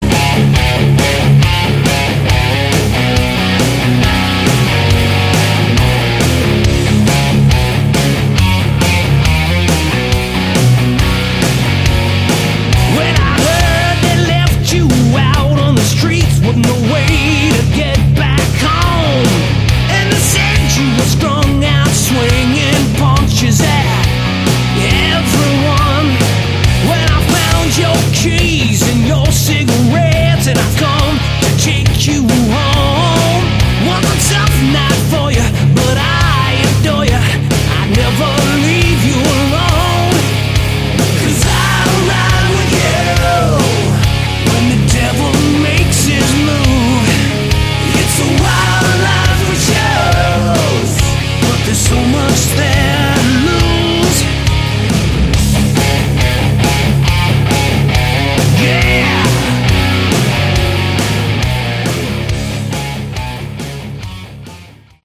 Category: Hard Rock
lead vocals, rhythm guitar
lead guitar, harmonica, backing vocals
bass guitar, backing vocals
drums, percussion, backing vocals